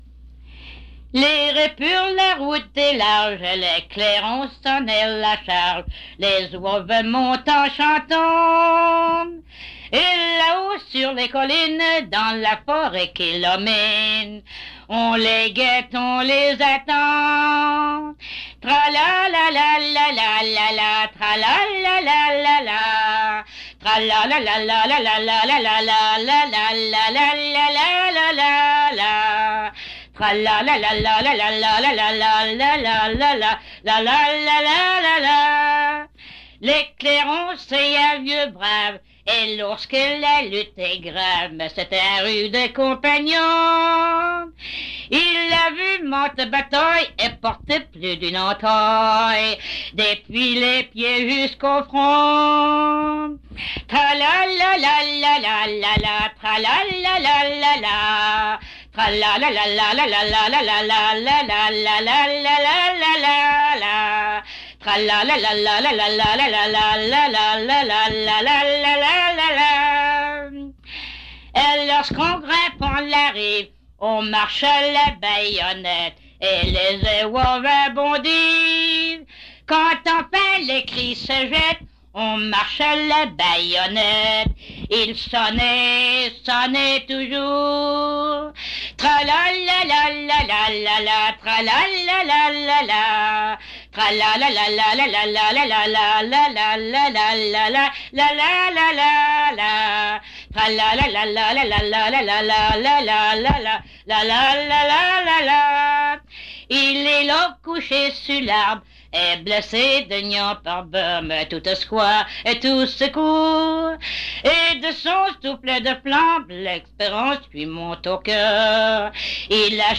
Chanson Item Type Metadata
Centre d'études franco-terreneuviennes (CEFT)
Emplacement Cap St-Georges